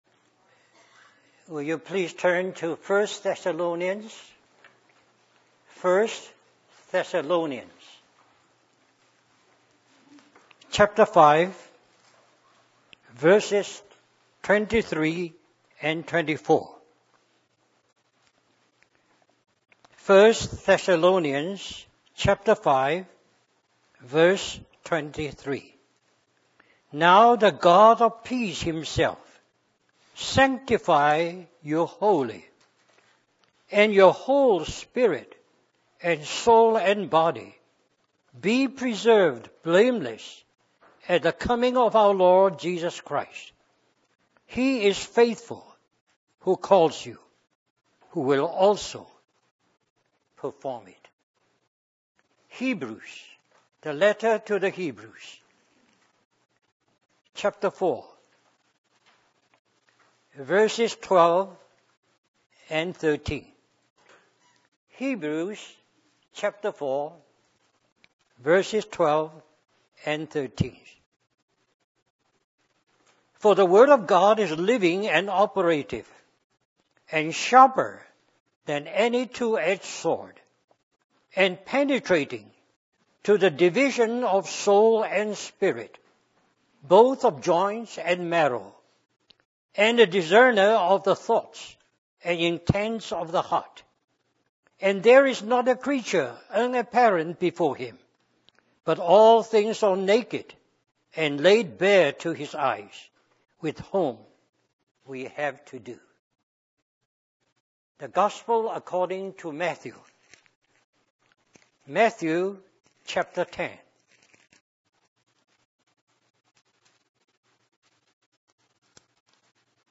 In this sermon, the preacher discusses the sinful nature of humanity and the consequences of disobedience. He explains that after the fall of man, humans became children of wrath and lived in the lusts of their flesh. The preacher also highlights the tripartite nature of humans, consisting of spirit, soul, and body.